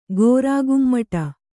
♪ gōrā gummaṭa